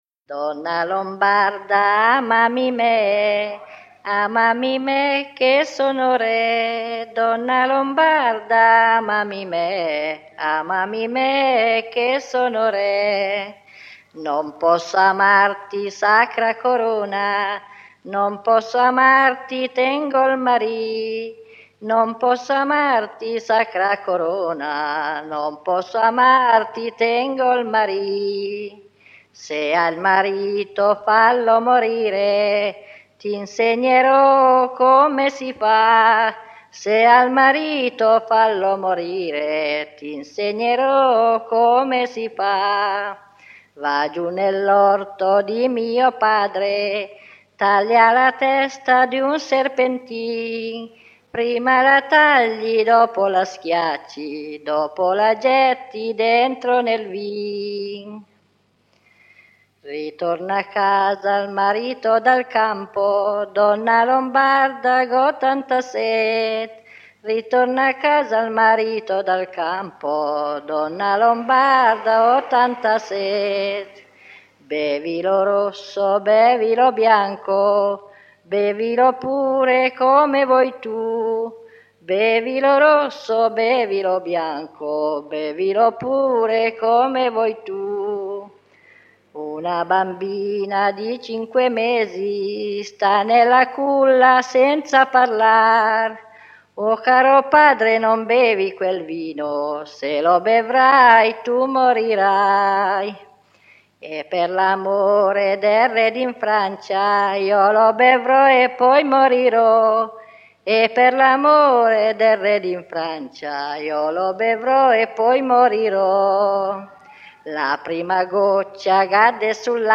Donna lombarda / [registrata a Suno (NO), nel 1963]